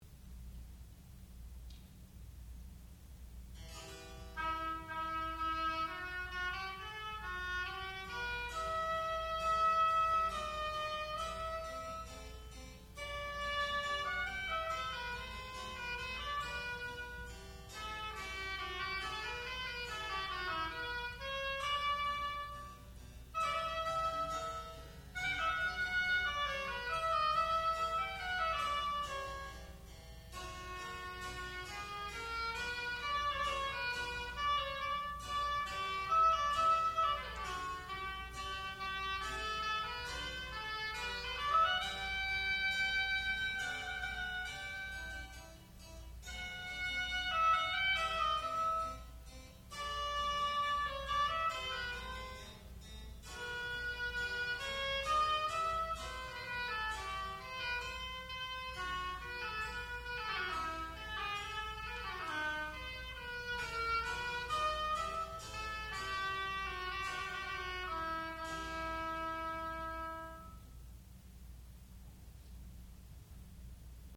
classical music
oboe
harpsichord
Graduate Recital